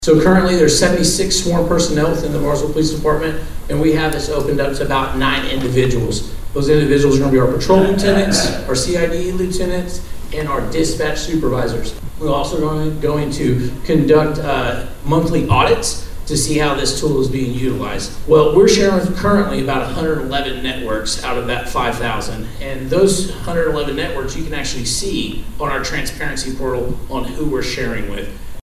More than 100 people attended a two-hour-long forum at Oklahoma Wesleyan University Thursday evening on the Bartlesville Police Department's use of Flock Automated License Plate Reader cameras.